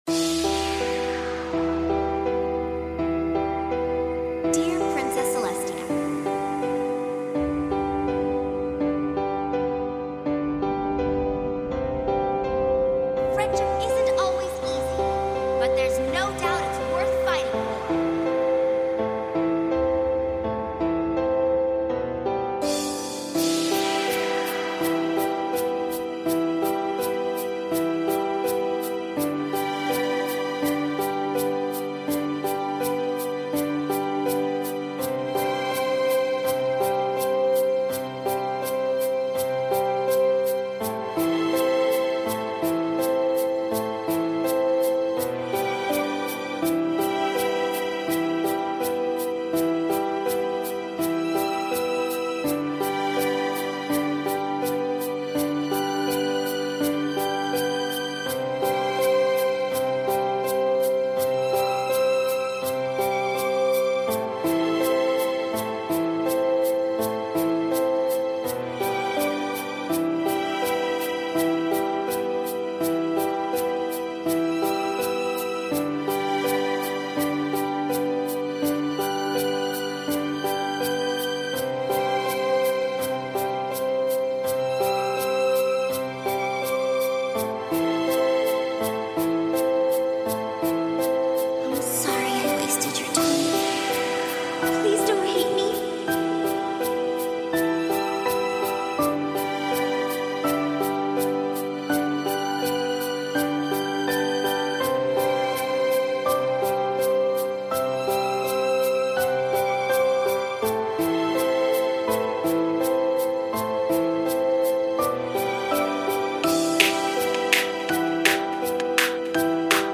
Its my first song to use pony samples that aren't singing